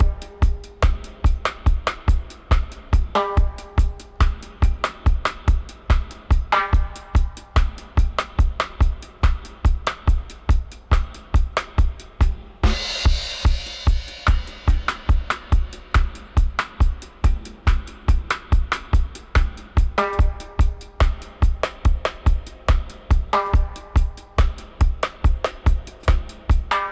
142-FX-02.wav